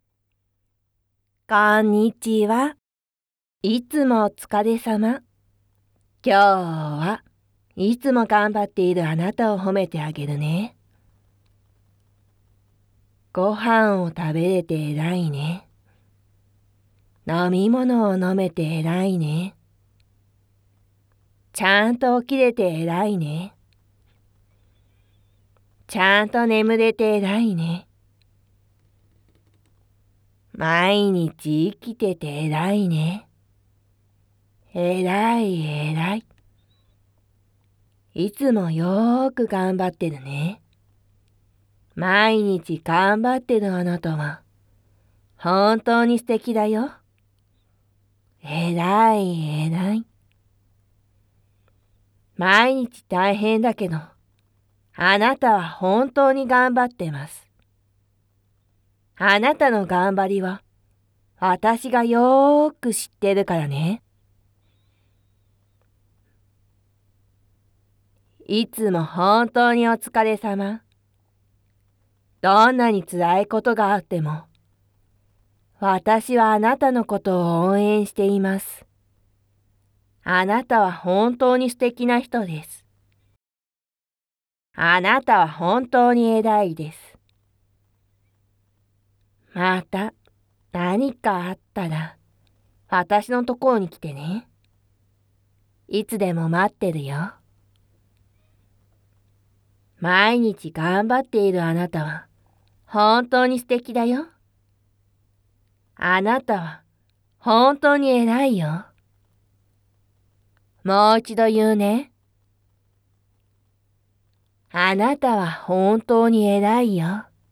纯爱/甜蜜 日常/生活 温馨 萌 健全 治愈 皆大欢喜 催眠音声